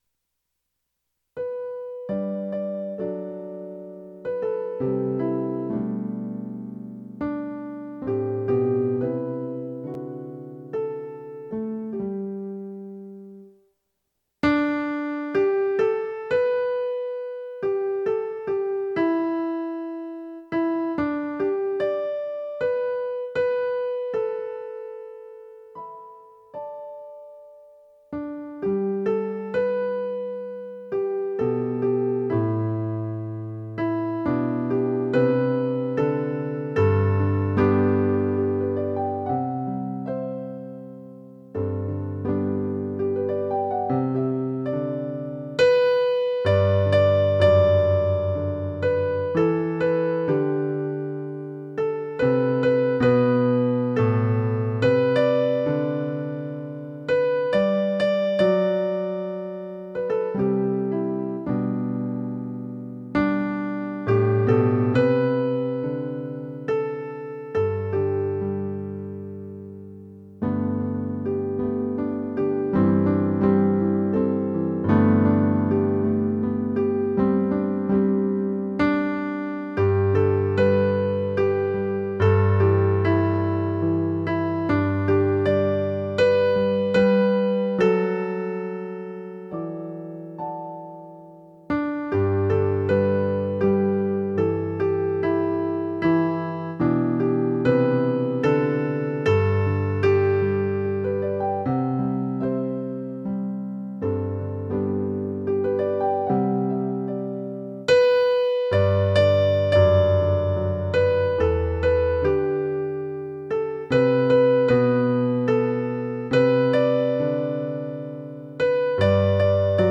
How Can I Keep From Singing? (arr. Andy Beck) - Soprano